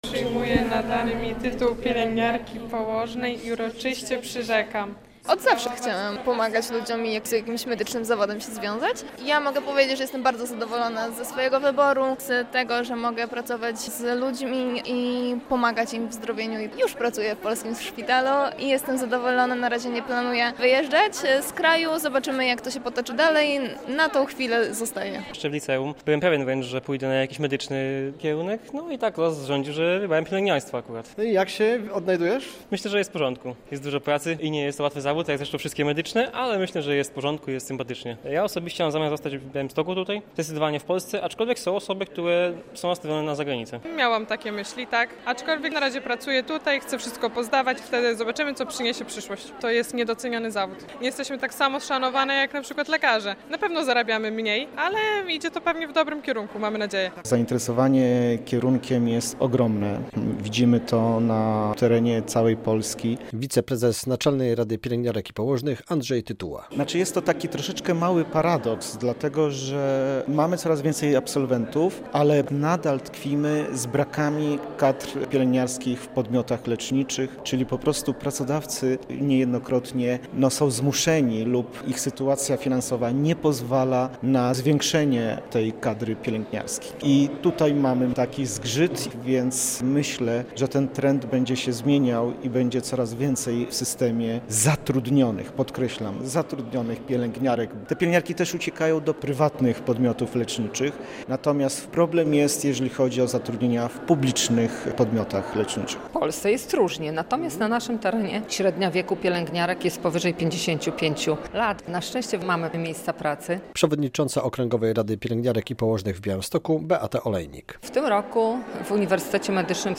Wzrasta zainteresowanie pielęgniarstwem - relacja